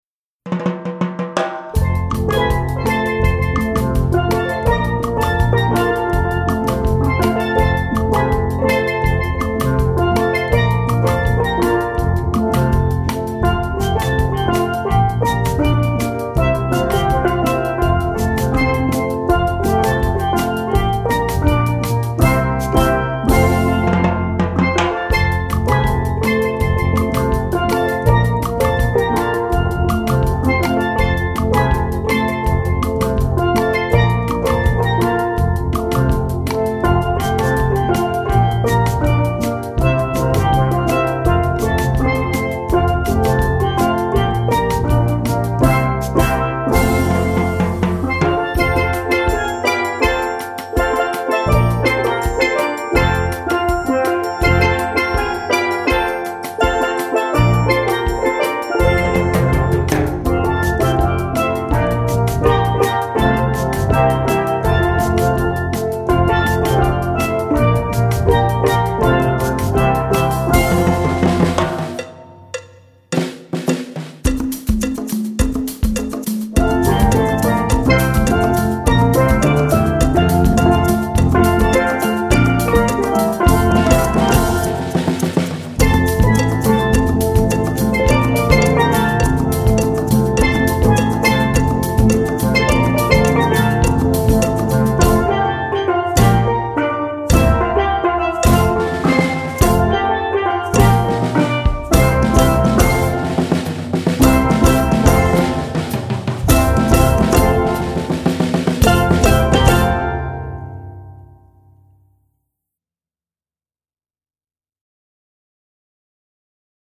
Medium Steel Drum Ensemble